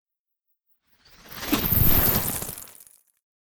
Free Frost Mage - SFX
ice_casting_14.wav